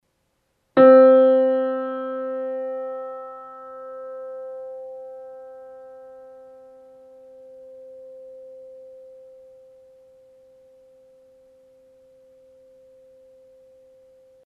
Petrov_middle_C.mp3